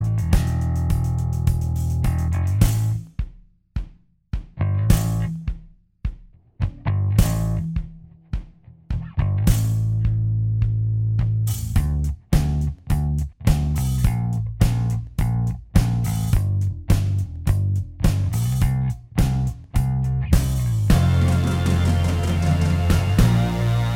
Minus Guitars